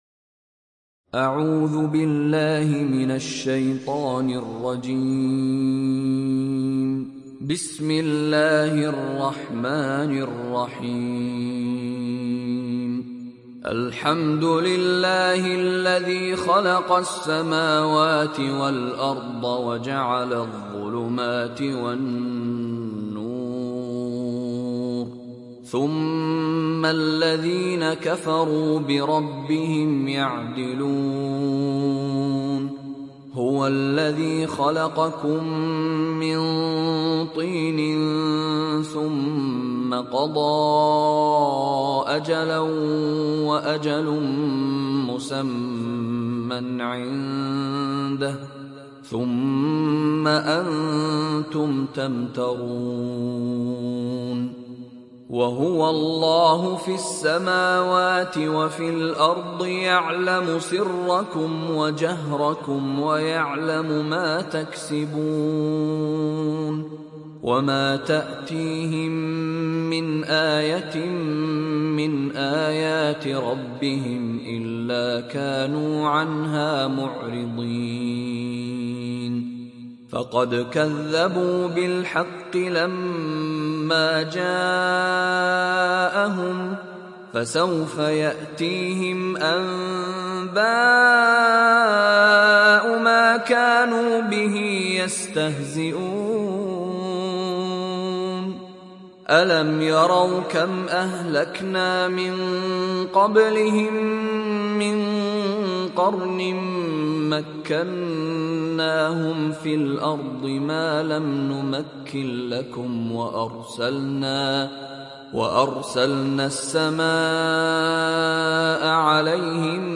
تحميل سورة الأنعام mp3 بصوت مشاري راشد العفاسي برواية حفص عن عاصم, تحميل استماع القرآن الكريم على الجوال mp3 كاملا بروابط مباشرة وسريعة